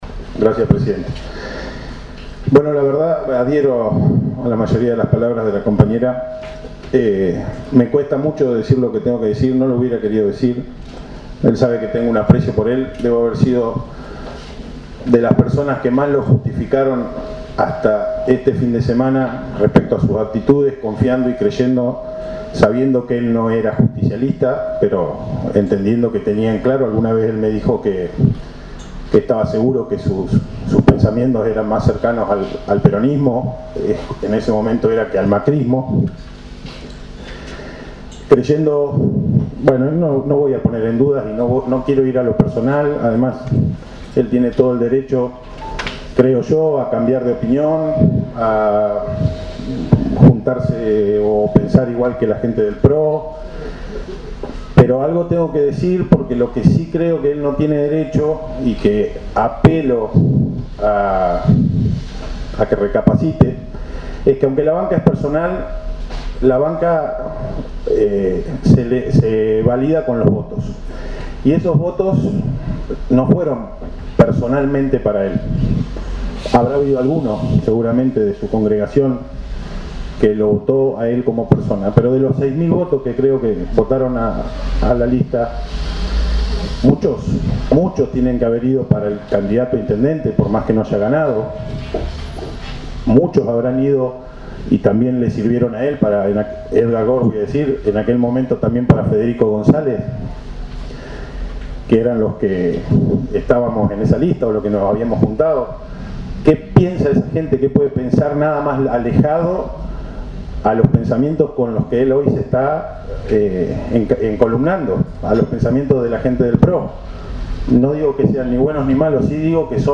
En la Hora de Preferencia del Concejo Deliberante de Rawson se produjo un fuerte reclamo desde la bancada del Frente de Todos hacia el edil de Despertar Rawsense, German Gusella, por su reciente vinculación al PRO.